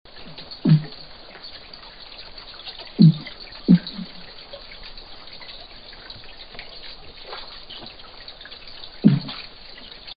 Sounds Made by Silurus glanis
Type of sound produced thumps, knocks, escape sounds
Sound production organ pneumatic duct & swim bladder
Sound mechanism not known but probably vibration of swim bladder & pneumatic duct caused by quick contraction of associated muscles
Behavioural context spontaneous exclusively nocturnal sound production associated with foraging behaviour
Remark outdoor recording (freshwater lake). Species-specific sounds, mostly single sounds emitted at great intervals. Recorded in presence of an unknown number of other species, late at night. Very noisy background - lowpass filter with corner-frequency of 5000 Hz.